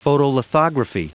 Prononciation du mot photolithography en anglais (fichier audio)
Prononciation du mot : photolithography